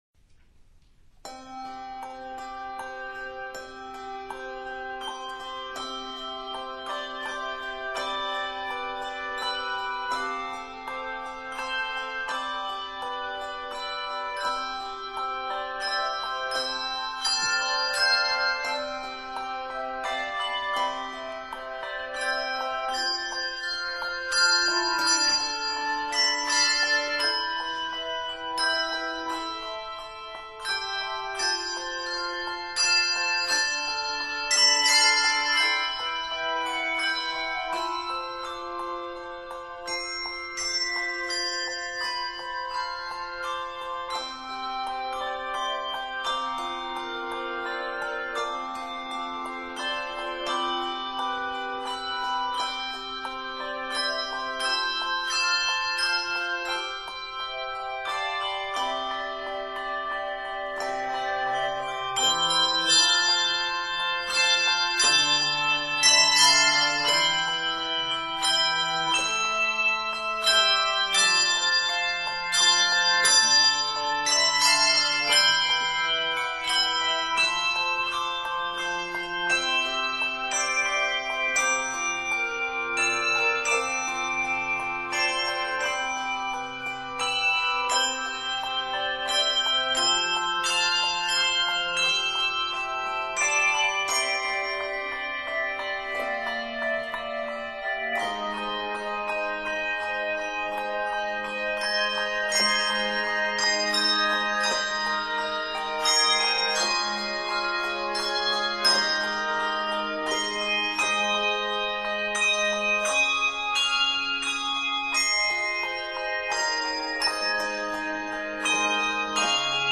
This is a beautiful, yet simple arrangement
A nice flowing piece that uses LV throughout.